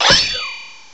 cry_not_honedge.aif